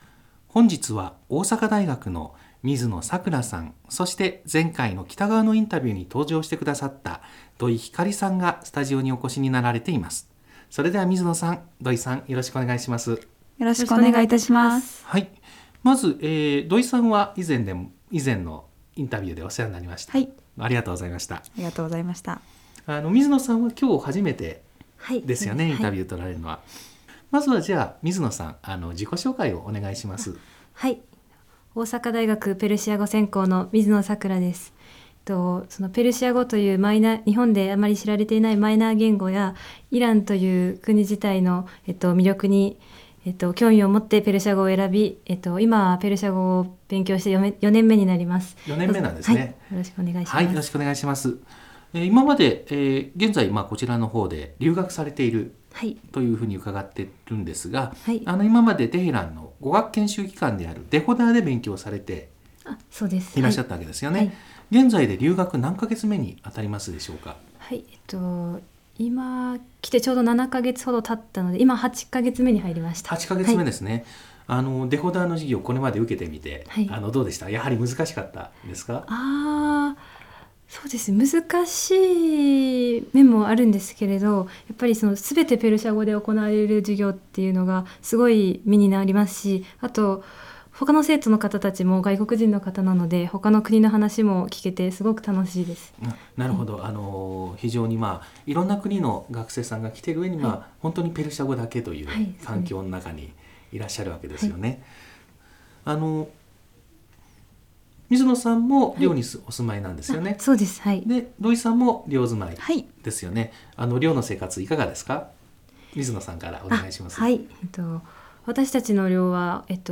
スタジオに来てのインタビューです。